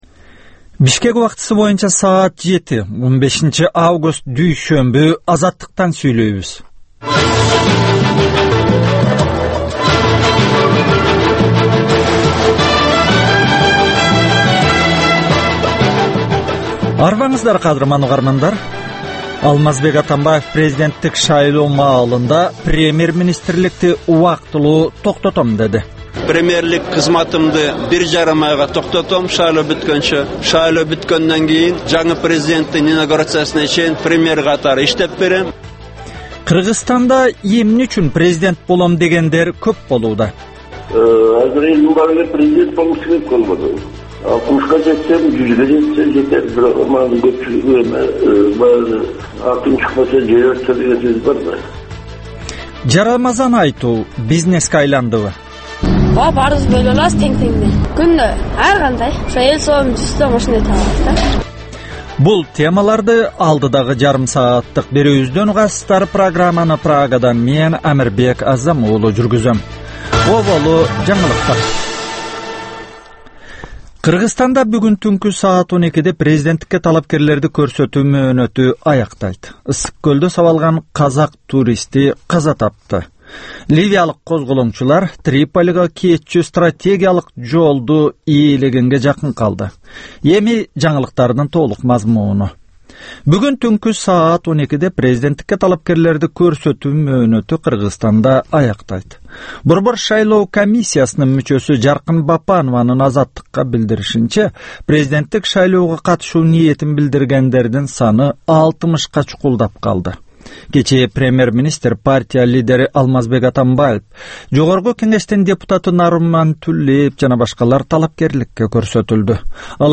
Таңкы 7деги кабарлар